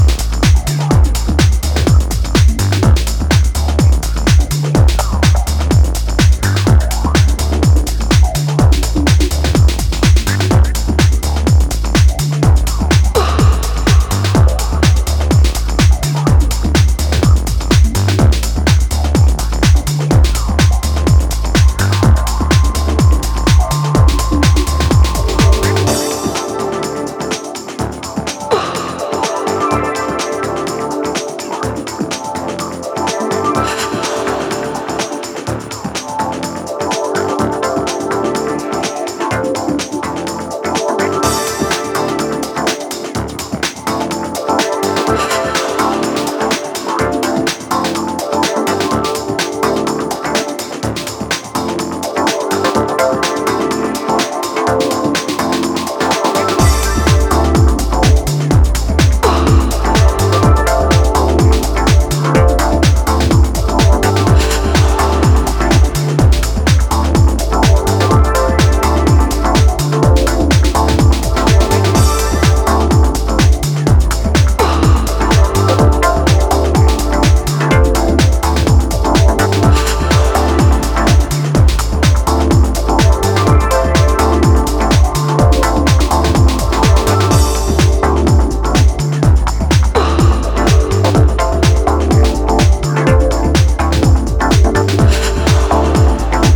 dives into a deep atmosphere